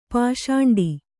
♪ pāśāṇḍi